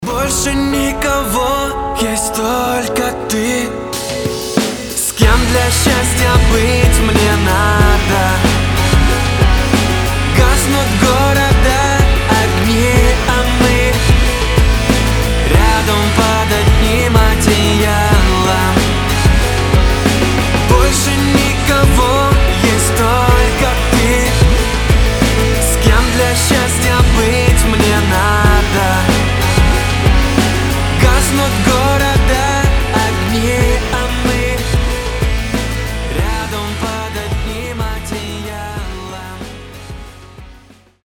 красивые
Очень романтичный рингтон